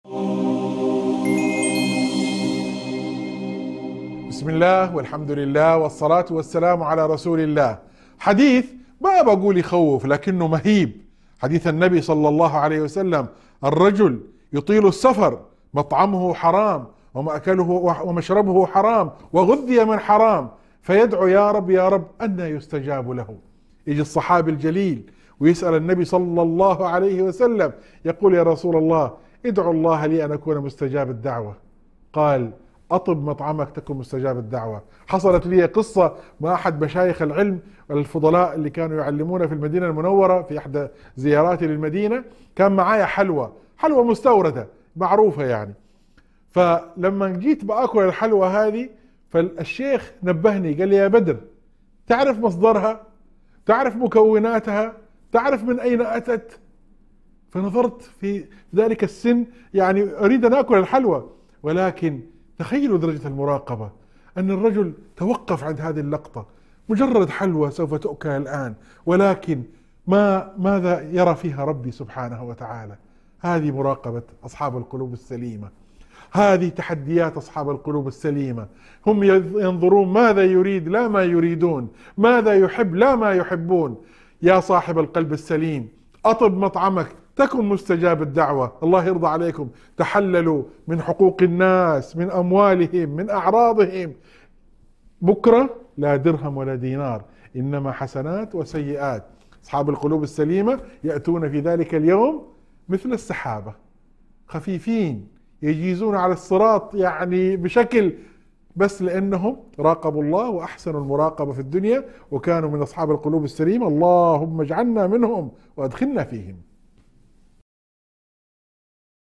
موعظة مؤثرة تشرح شرط استجابة الدعاء وهو أكل الحلال ومراقبة الله في السر والعلن. تذكر بفضل أصحاب القلوب السليمة الذين ينجون يوم القيامة ويمرون على الصراط بسهولة بسبب إحسانهم المراقبة لله في الدنيا.